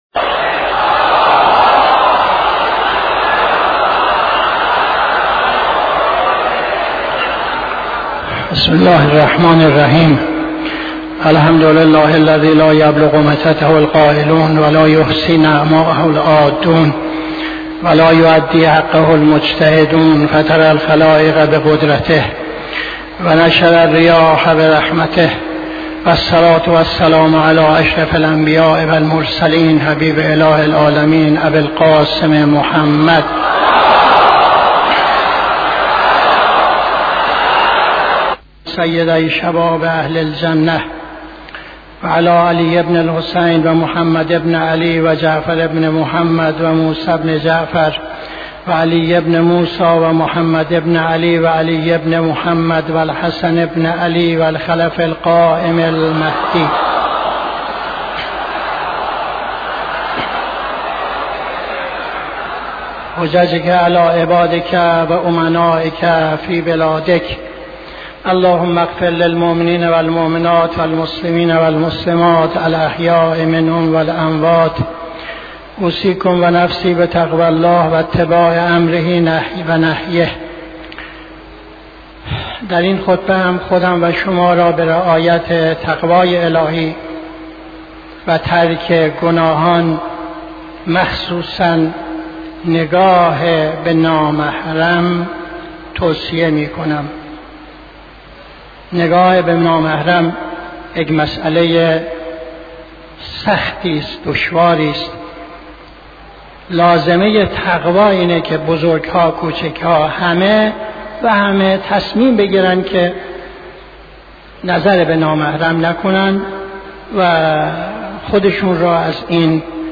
خطبه دوم نماز جمعه 16-02-79